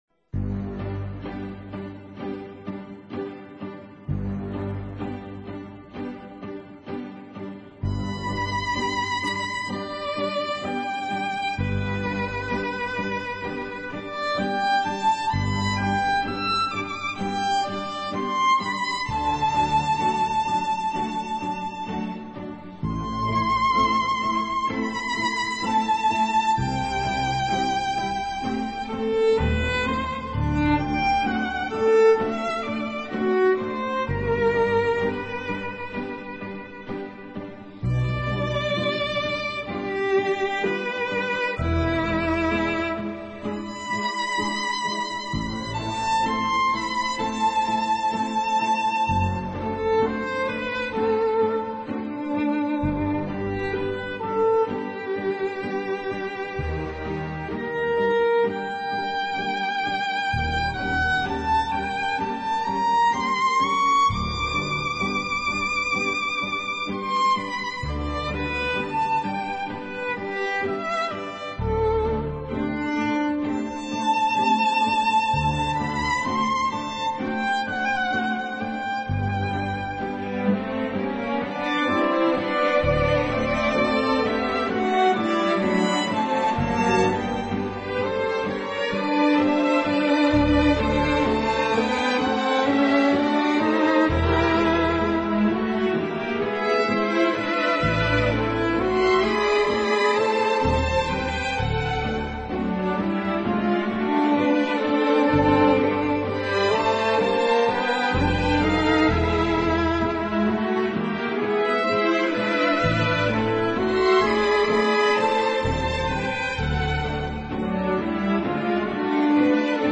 这就是古典元素（Oboe、Flauto、Corno、Archi、Pianoforte）
和现代元素（Batteria，Chitarra Basso，Synth）如何在作品当中相互融合的